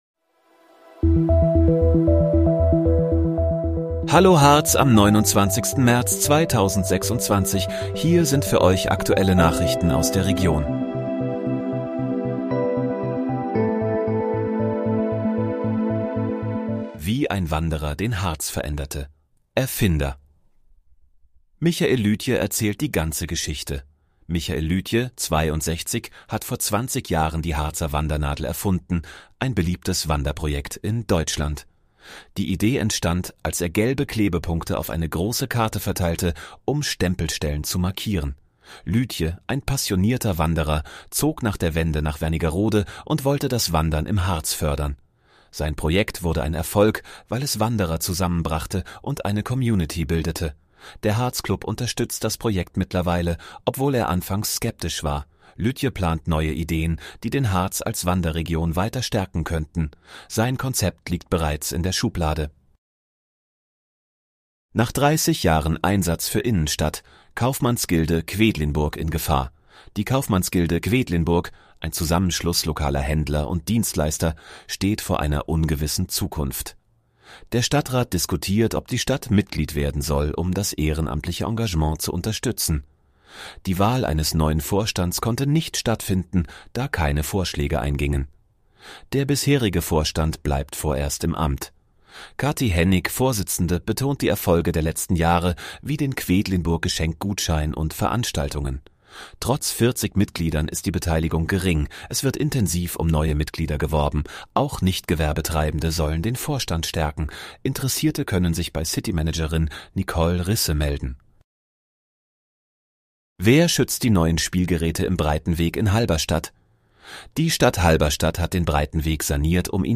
Hallo, Harz: Aktuelle Nachrichten vom 29.03.2026, erstellt mit KI-Unterstützung